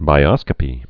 (bī-ŏskə-pē)